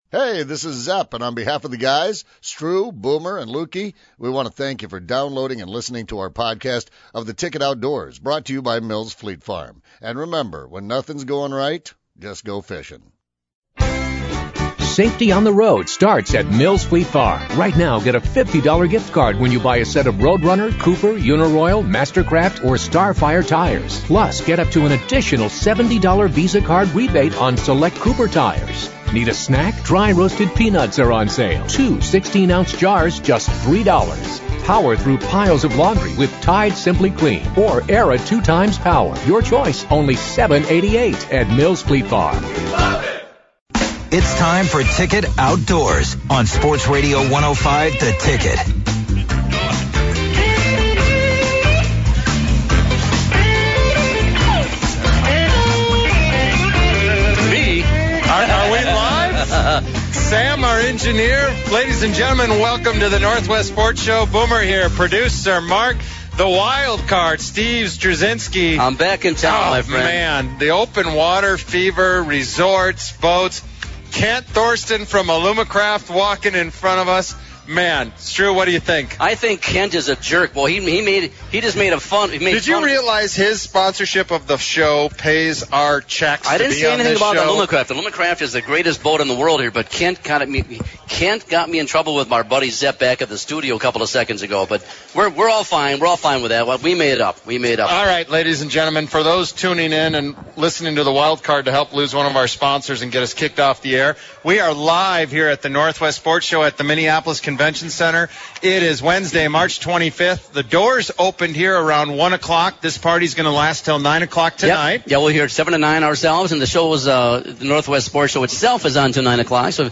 Live from the Northwest Sports Show at the Minneapolis Convention Center.